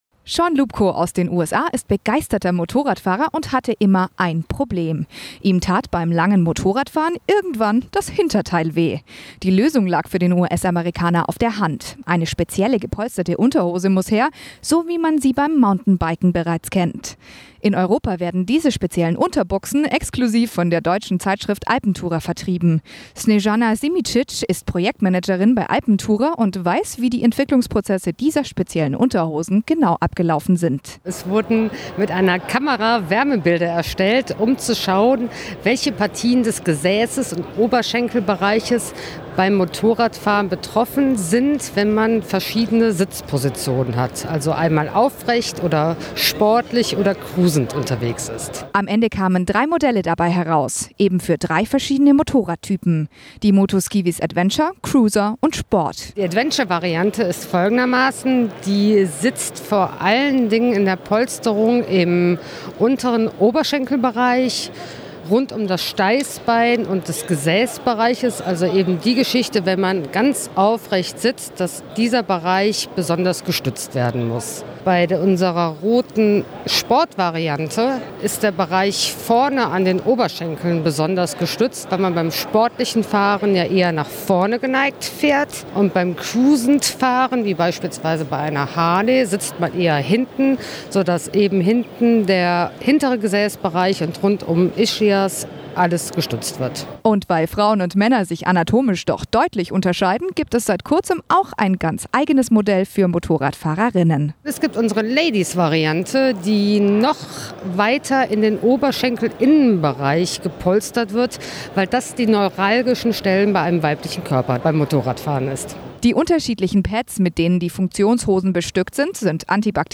motorrad-live-stuttgart-2017
Interview zu Moto-Skiveez®